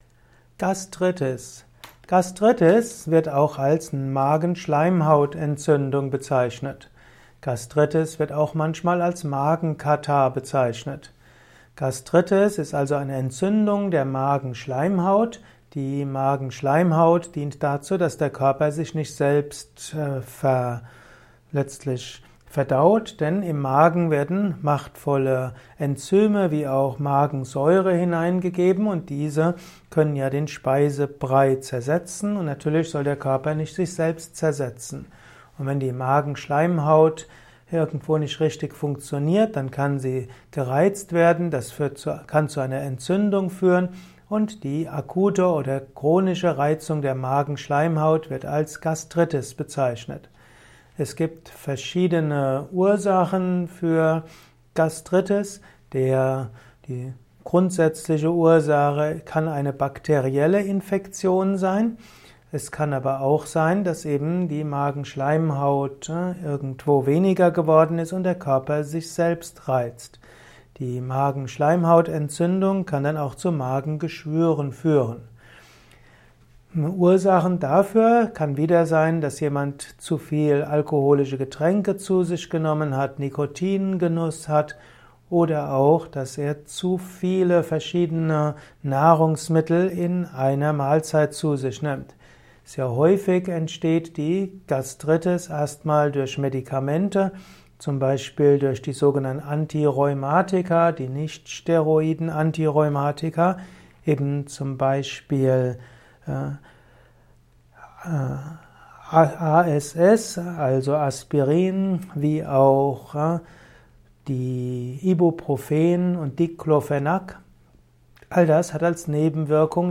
Ein Kurzvortrag über Gastritis